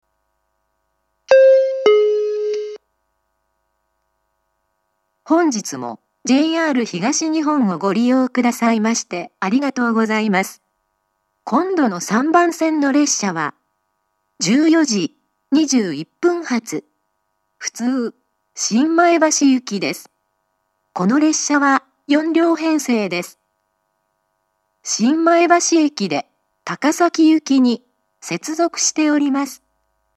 ３番線次発放送 14:21発普通新前橋行（４両、新前橋で高崎行接続）の放送です。